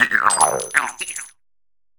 Cri de Mordudor dans sa forme Marche dans Pokémon HOME.
Cri_0999_Marche_HOME.ogg